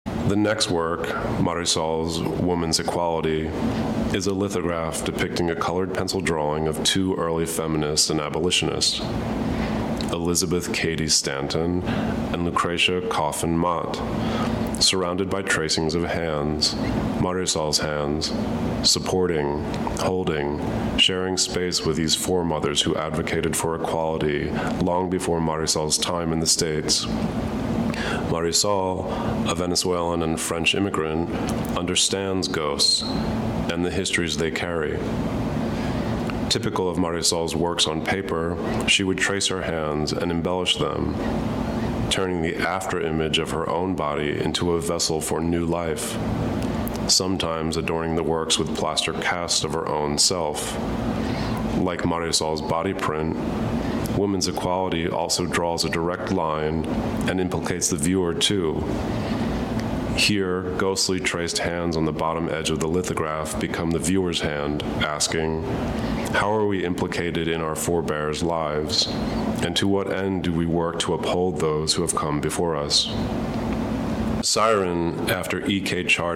Clicking on it will let you listen to Da Corte himself as he comments on a work or on his own technique.